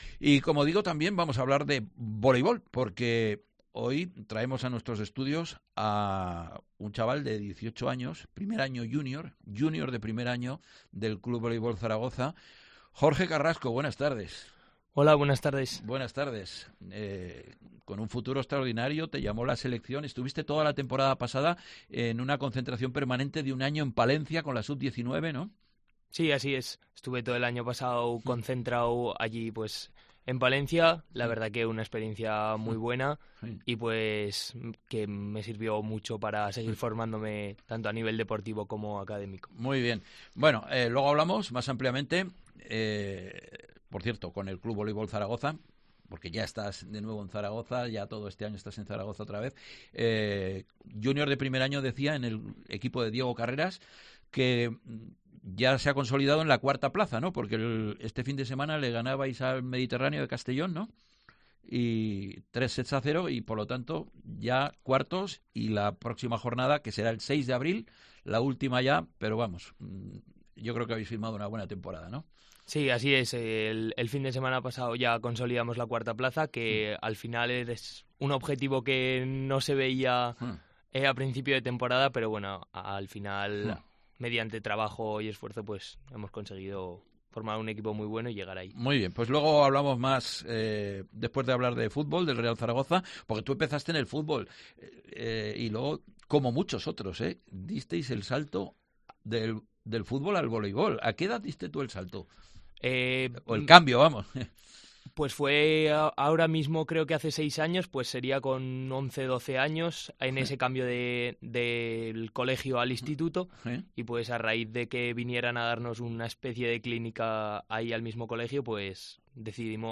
Deportes en Zaragoza Entrevista